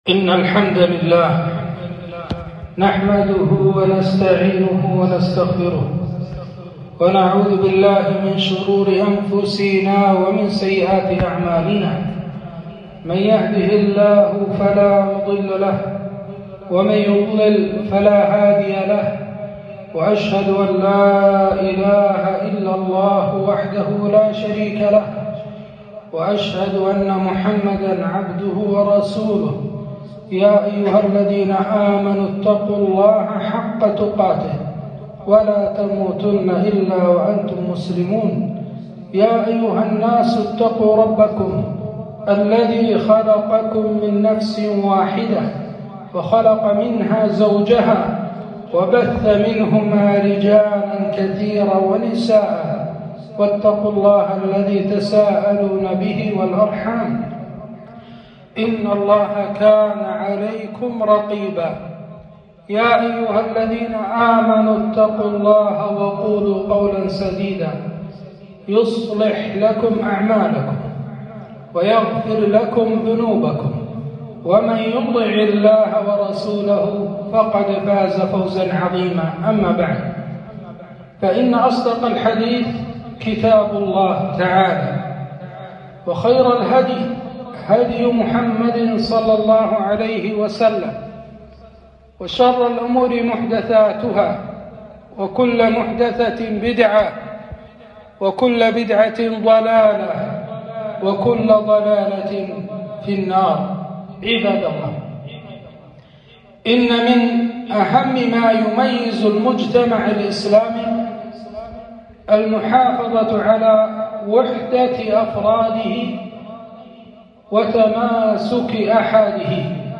خطبة - حقوق الجار في الإسلام